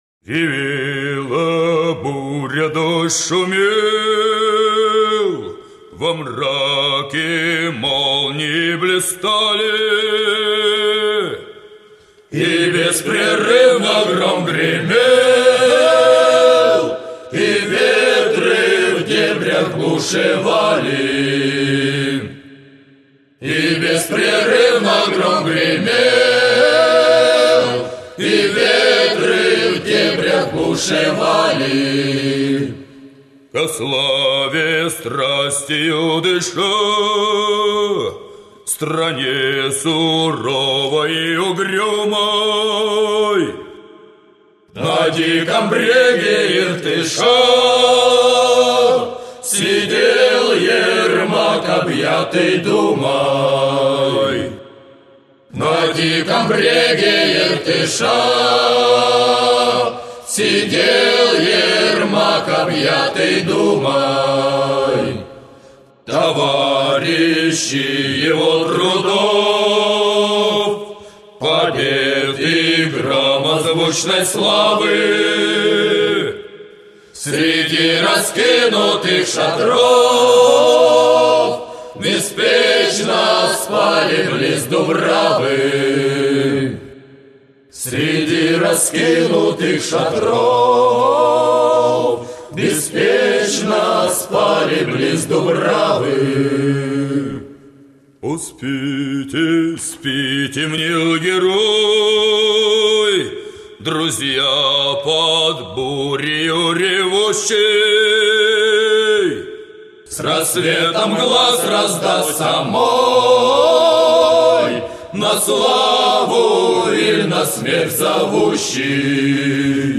«Смерть_Ермака»_в_исполнении_Кубанского_казачьего_хора.mp3